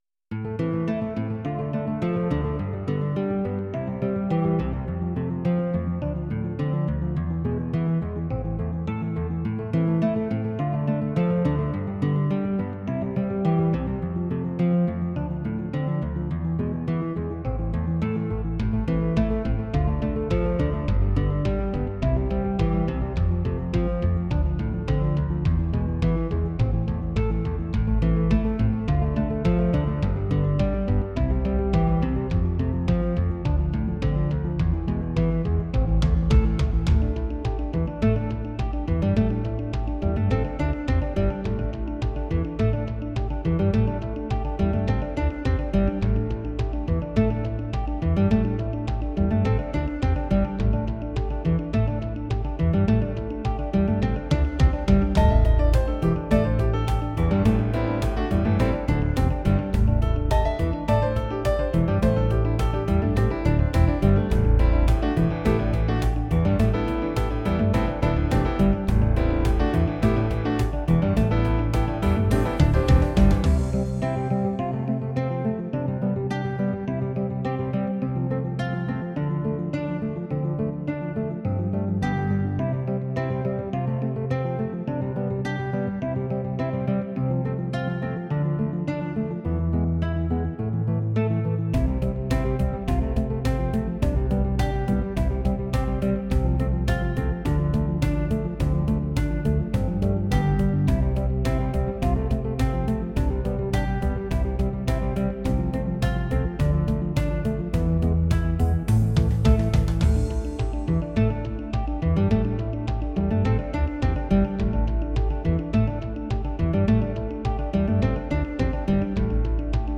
Playback-Audio (part of the pack):